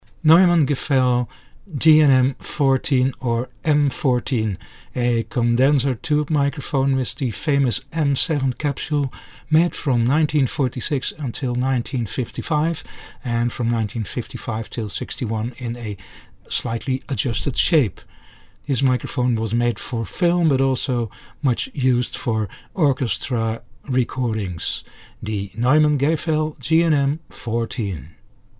Listen to the sound of the GNM 14
Neumann Gefell GNM 14 sound UK 2i2.mp3